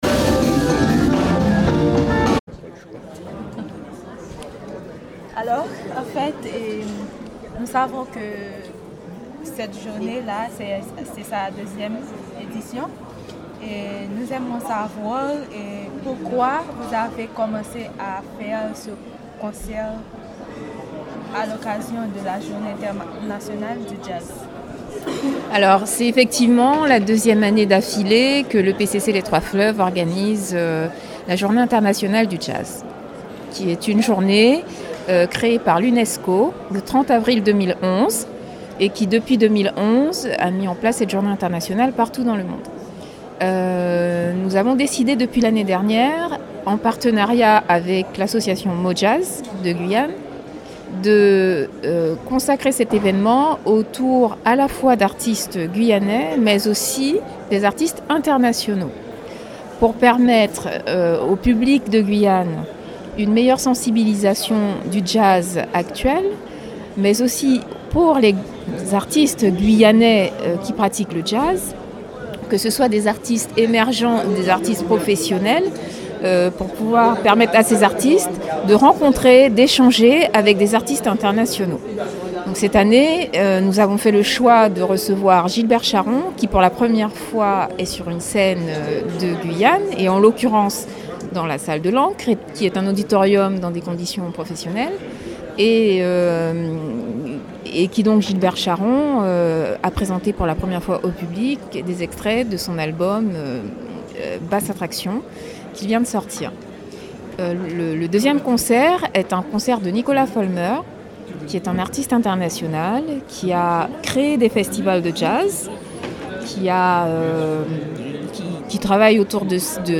en interview sur Radio Mayouri Campus.